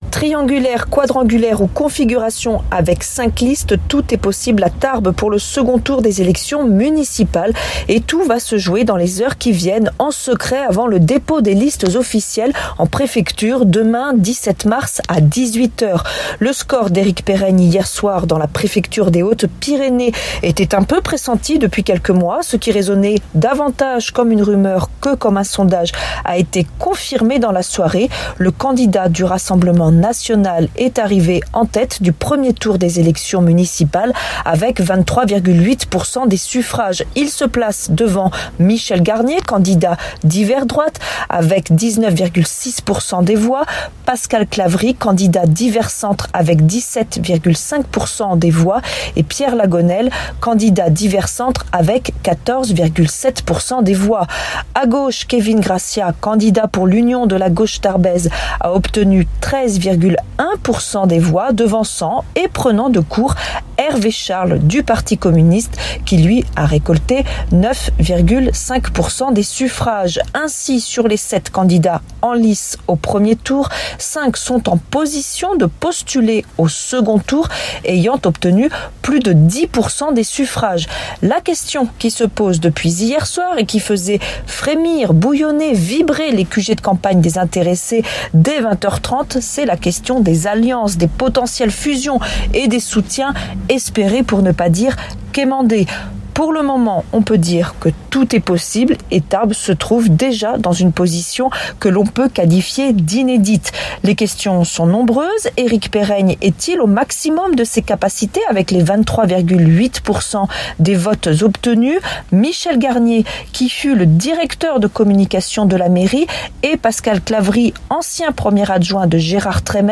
lundi 16 mars 2026 Interview et reportage Durée 10 min